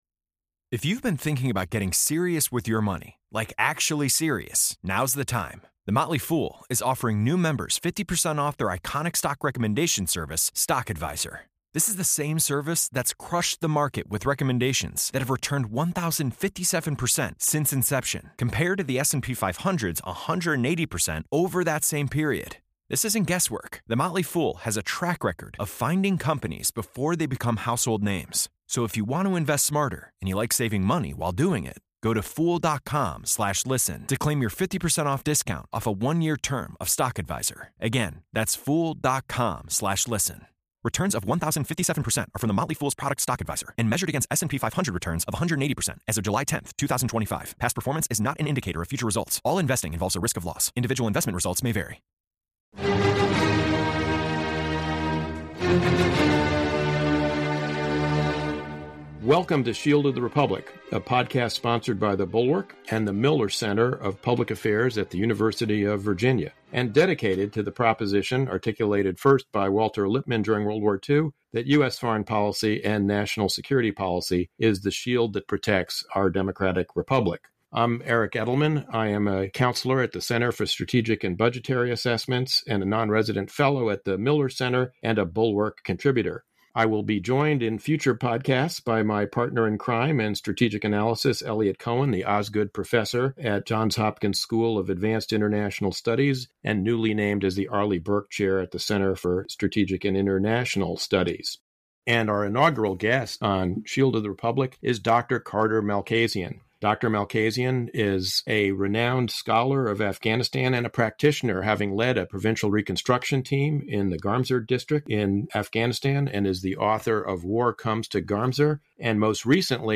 A discussion of why the war in Afghanistan was in General Mark Milley’s words “a strategic failure,” why the Afghan government failed to inspire Afghans to fight for their own country, and the legacy of former ambassador and peace agreement negotiator Zalmay Khalilzad.